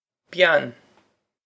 bean byan
Pronunciation for how to say
This is an approximate phonetic pronunciation of the phrase.